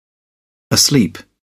asleep kelimesinin anlamı, resimli anlatımı ve sesli okunuşu